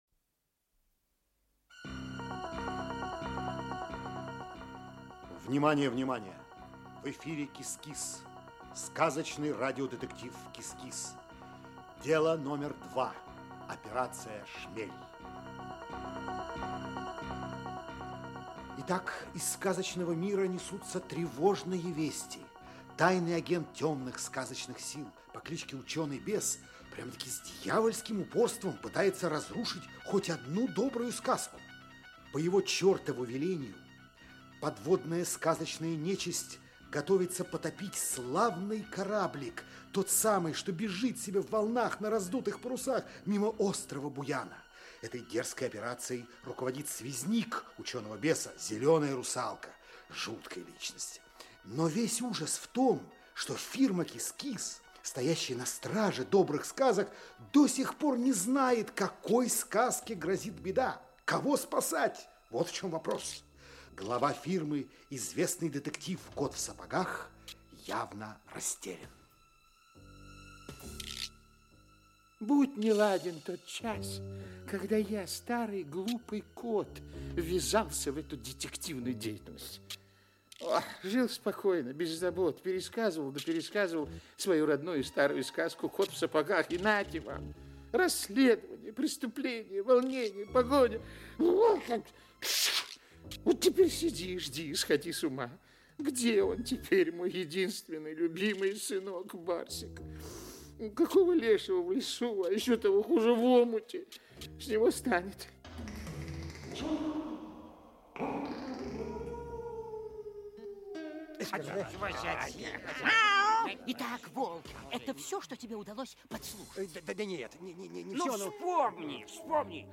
Часть 4 Автор Зоя Чернышева Читает аудиокнигу Александр Леньков.